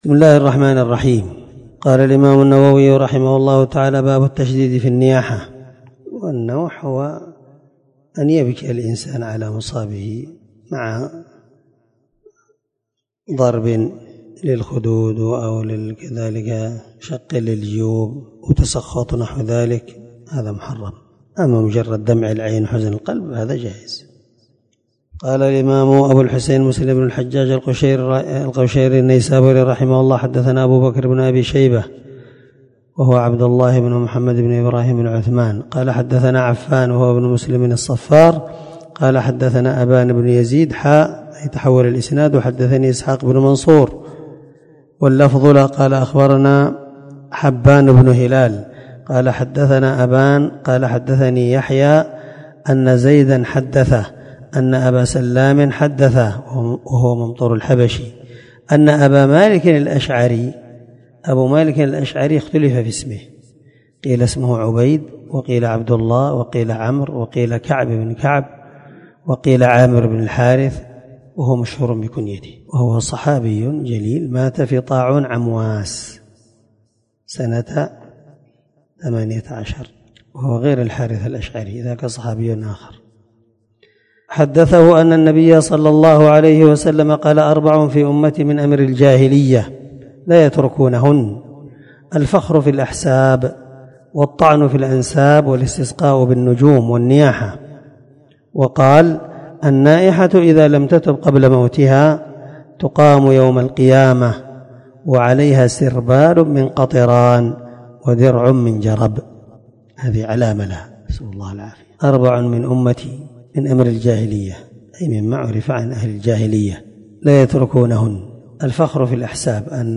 • سلسلة_الدروس_العلمية